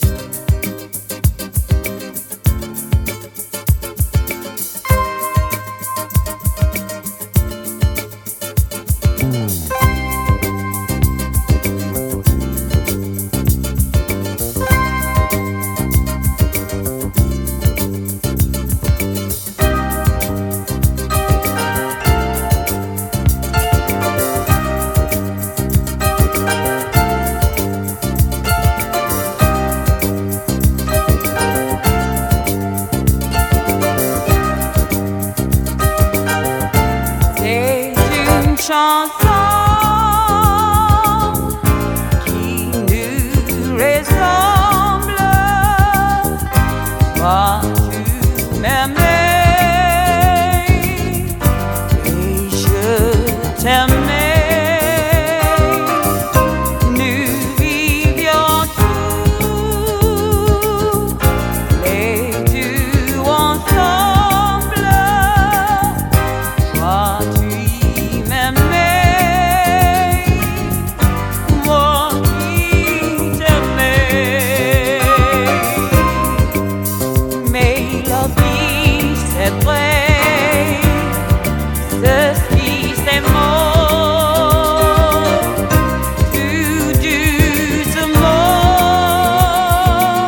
ROCK / 60'S / 60'S BEAT / BRAZIL (BRA)
珍しいブラジルの60'Sビート！